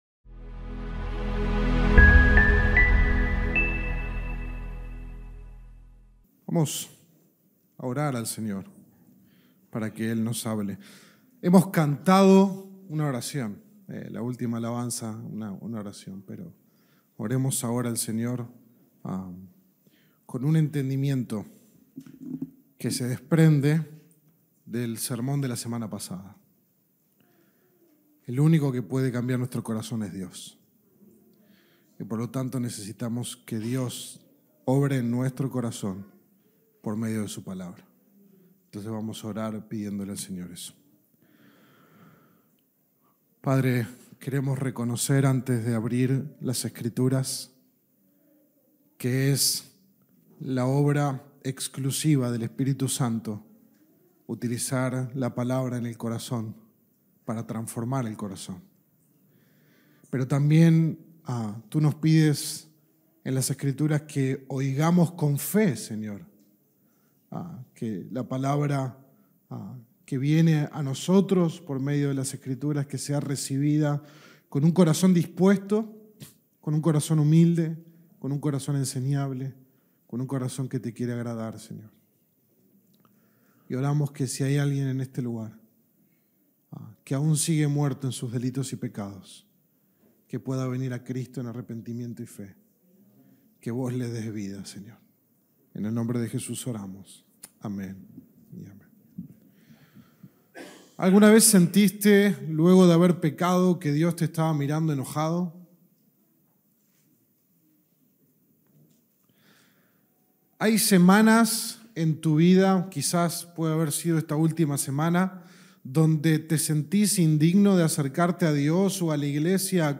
Sermón 23 de 33 en Sermones Individuales